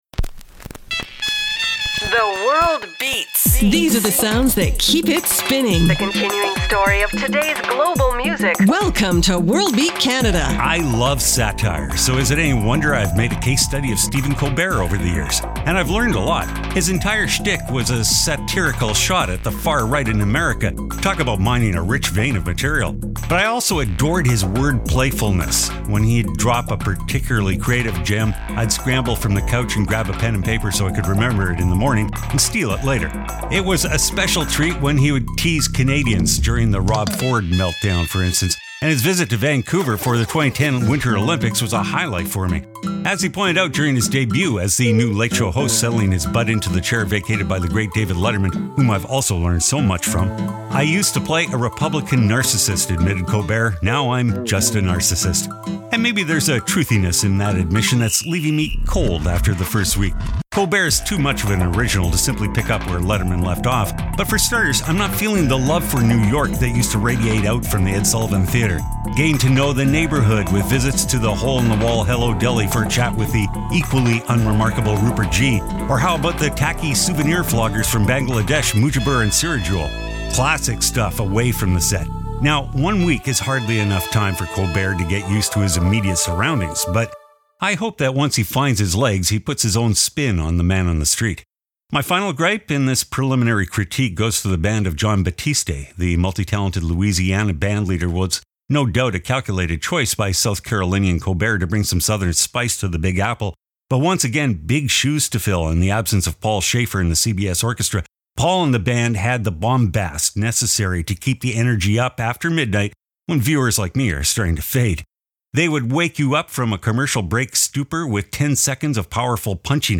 Bass-heavy